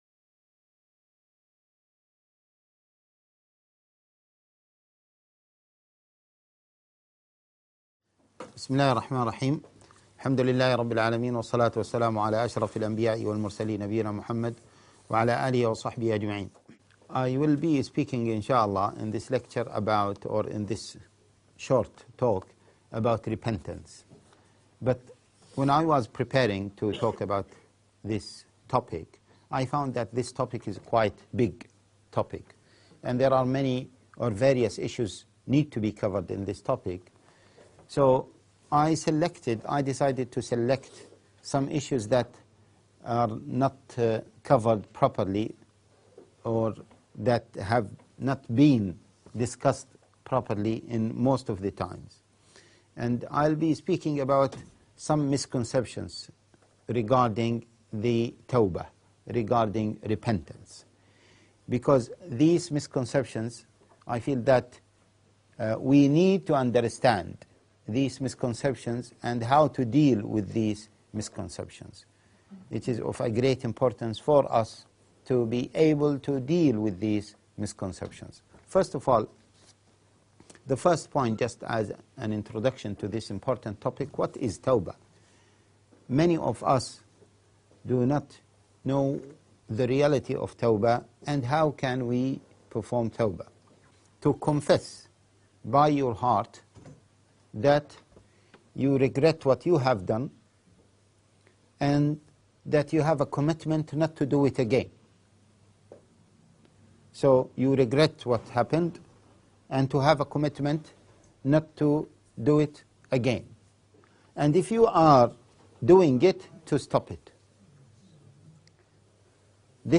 This lecture addresses common misconceptions about Tawbah and provides a practical guide to making repentance a daily habit.